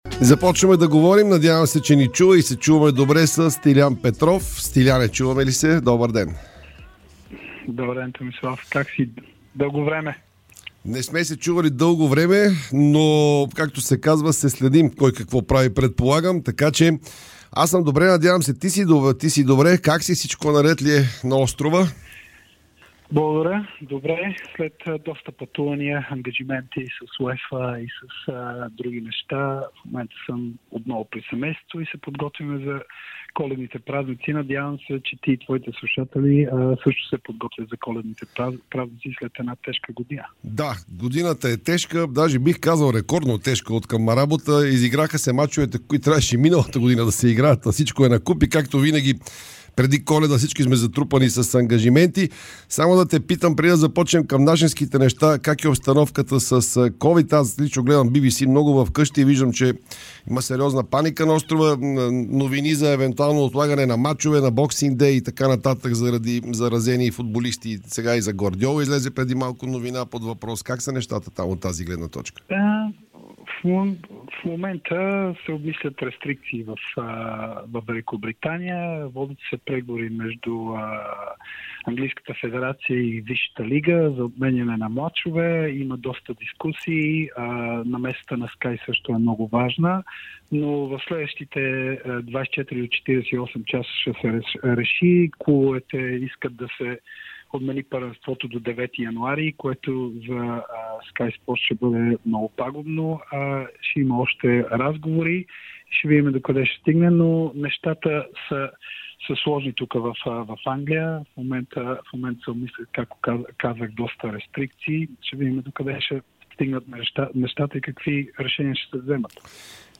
Бившият капитан на националния отбор и част от екипа на Димитър Бербатов за ново ръководство на БФС Стилиян Петров даде специално интервю за Дарик радио и dsport. В него легендарният халф говори за предстоящия конгрес на централата, който е насрочен за март, за развитието на родния футбол, както и представянето на националния тим.